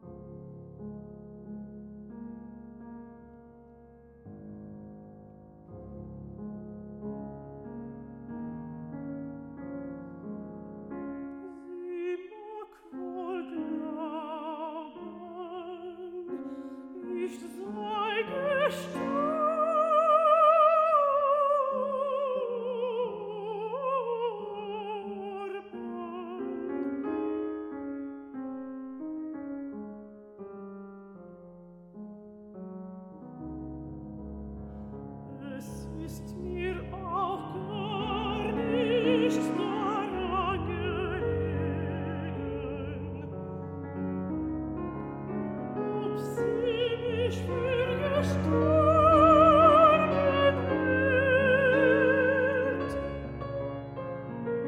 一場令人難忘的音樂會。
與生俱來且年輕的音質與體力，
使這位演唱家能夠充分控制、並相當細緻的處理每段語句與表情。
在2000年伊莉莎白女王音樂大賽的獲獎音樂會實況，